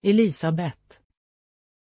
Pronunciation of Elizabeth
pronunciation-elizabeth-sv.mp3